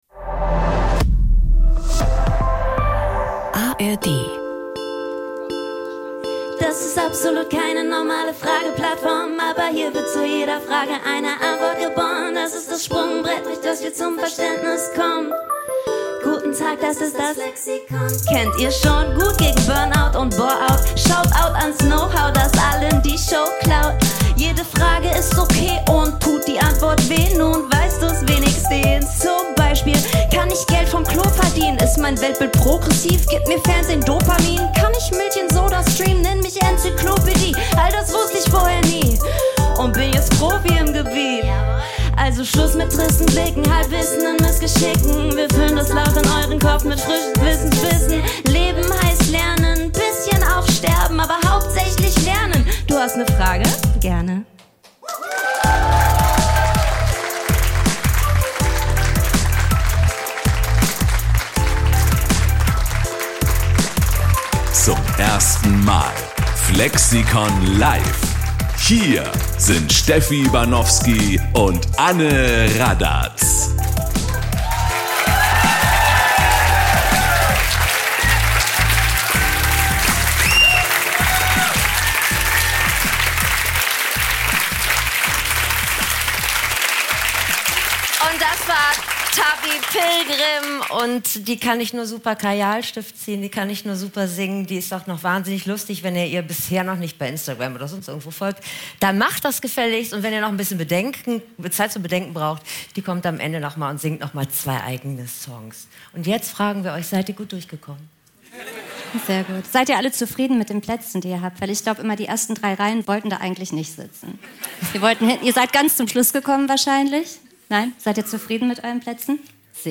#108 Flirten: Kann man das lernen? (Live)